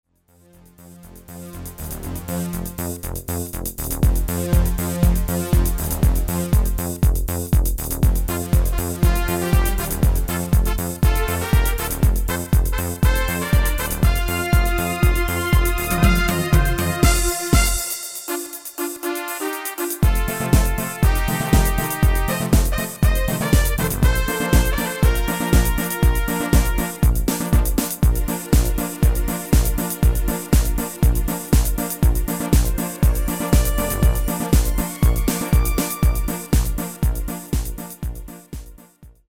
Demo/Koop midifile
Genre: Nederlands amusement / volks
- Géén vocal harmony tracks